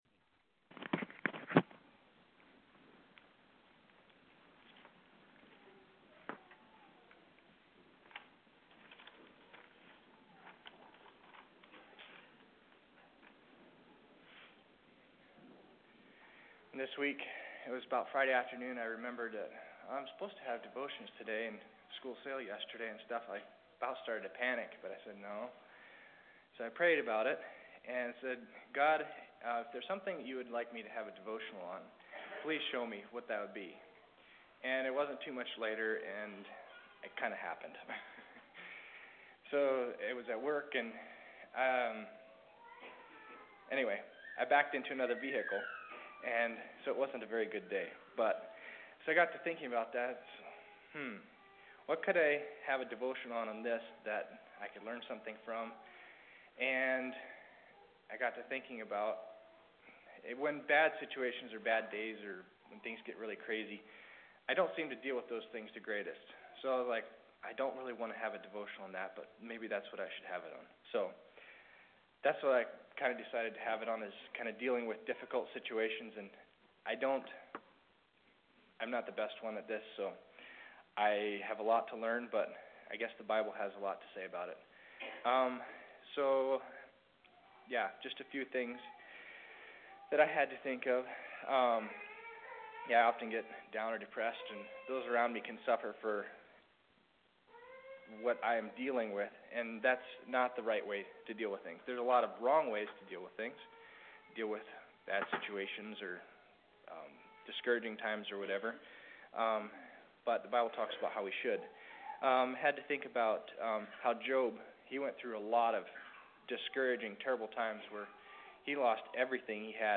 Devotions